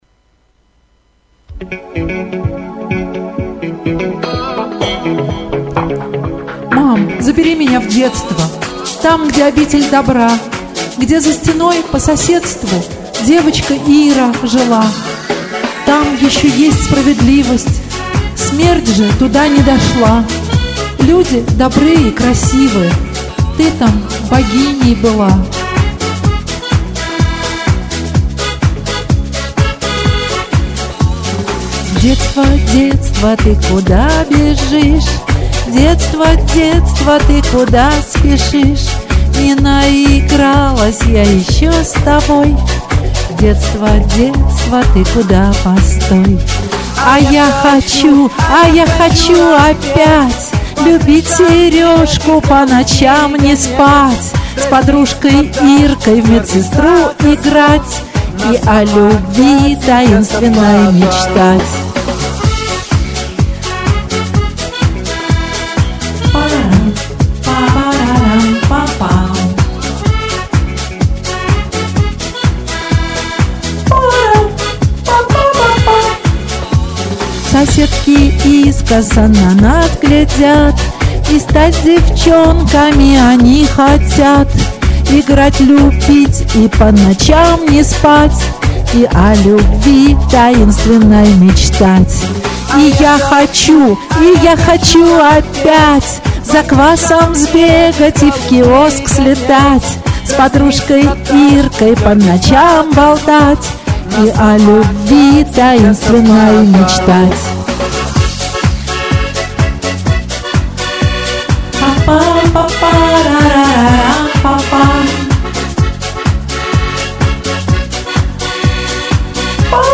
многовато реверба.